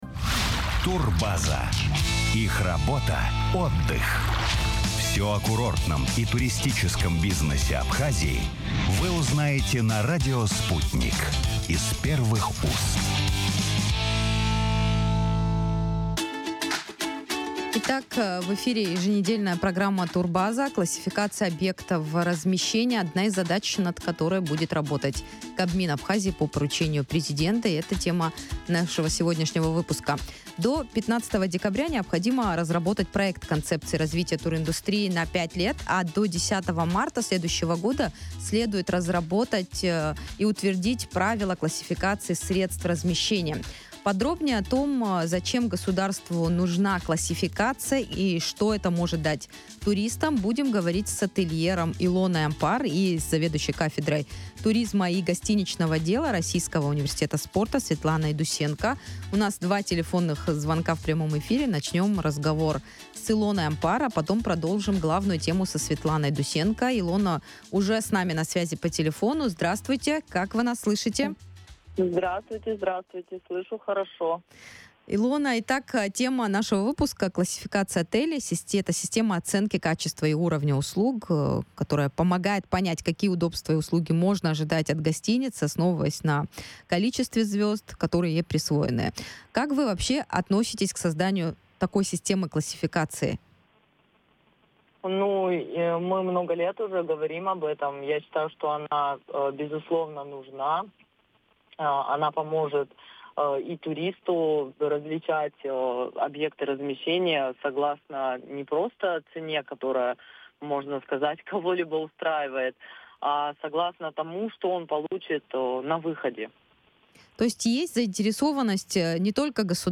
Поручения президента правительству Абхазии: разговор с министром туризма